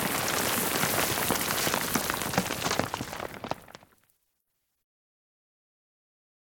sounds / weapons / _boom / mono / dirt8.ogg
dirt8.ogg